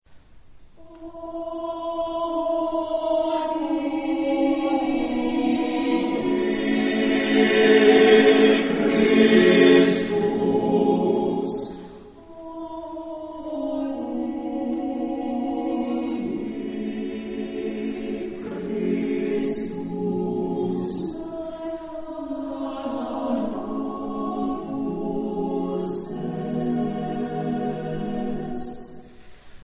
Género/Estilo/Forma: Canto coral ; Sagrado
Tipo de formación coral: SSAATTBB  (8 voces Coro mixto )
Instrumentos: Bongo (1)